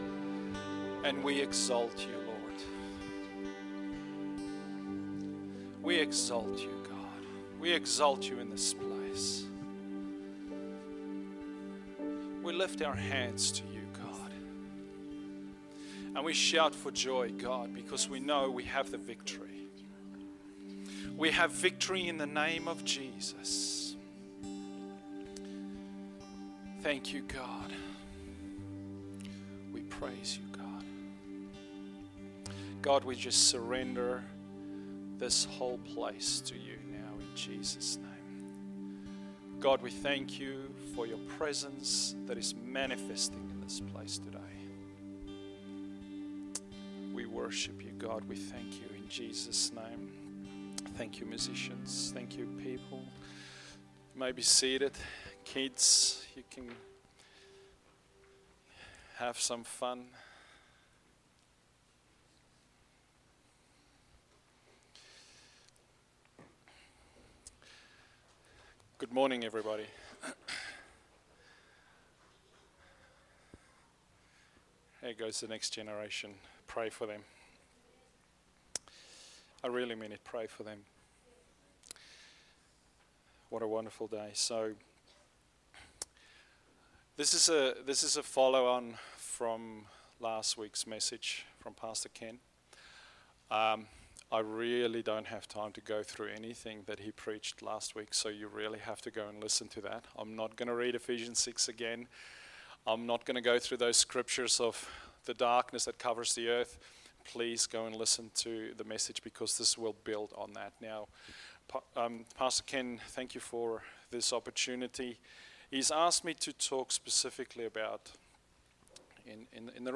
Sunday Message – Spiritual Warfare